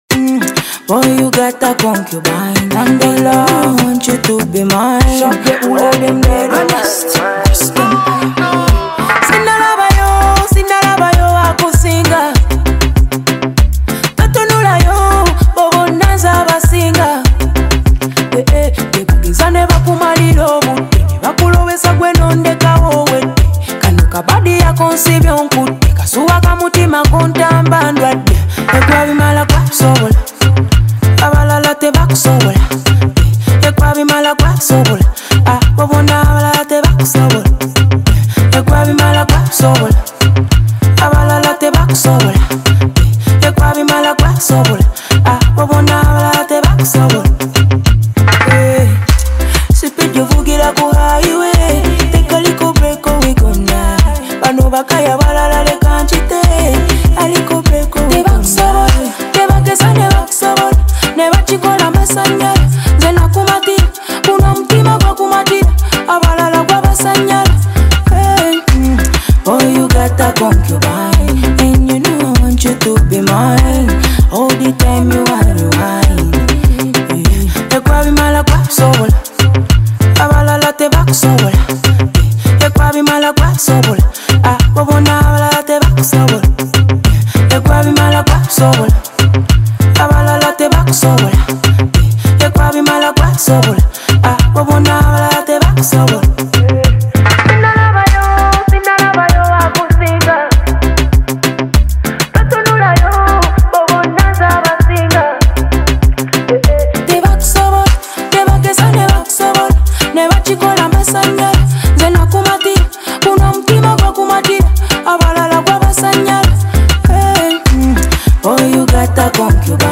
powerful Afrobeat single
With bold vocals and a fierce beat